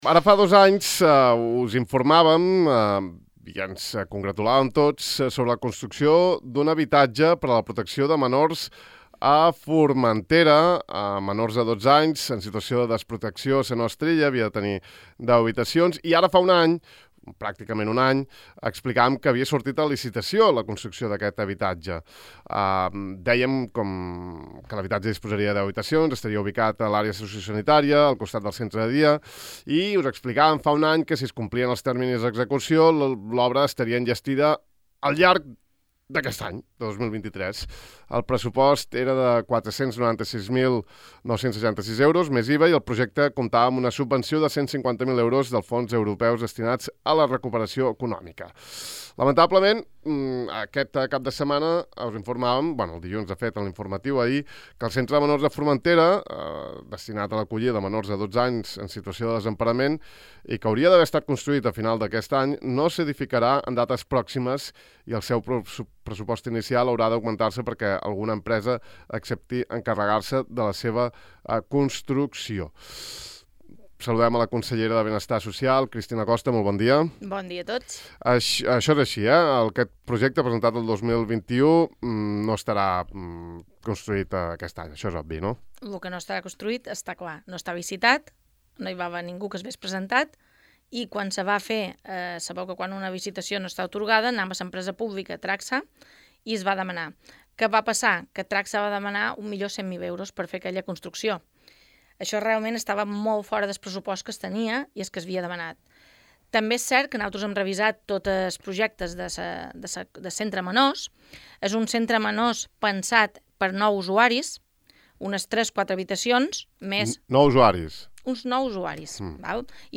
La consellera Costa, afirma en l’entrevista que espera trobar, perquè la intenció de l’actual Consell és seguir amb el projecte iniciat en la legislatura passada per l’anterior equip de govern, un licitador per uns 700.000 euros.